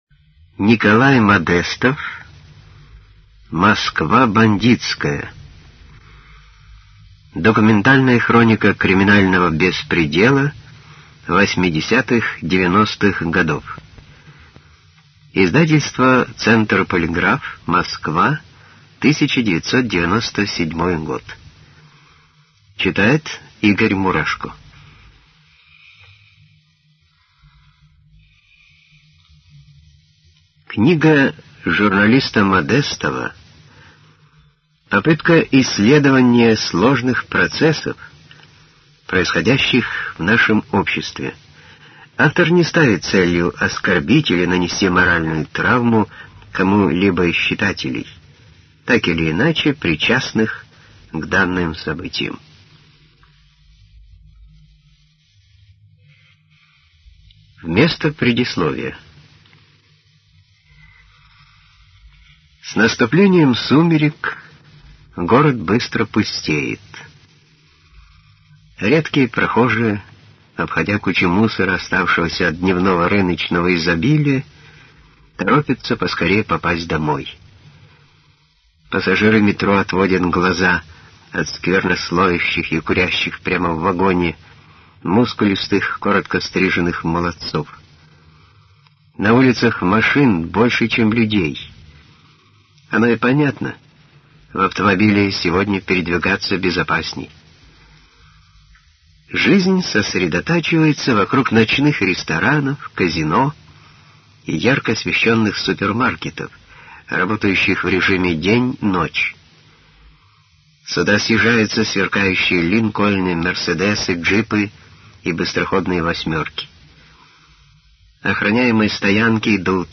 Николай Модестов «Москва бандитская» АУДИОКНИГА